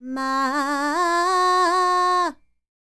QAWALLI 07.wav